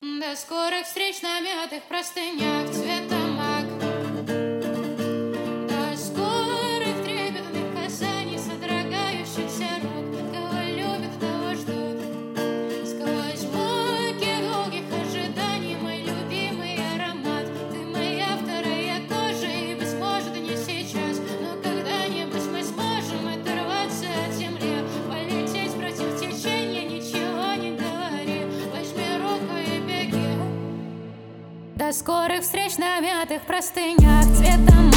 Жанр: Поп / Инди / Русские
# Indie Pop